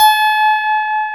Index of /90_sSampleCDs/Roland - Rhythm Section/KEY_Pop Pianos 4/KEY_Pop Pno + EP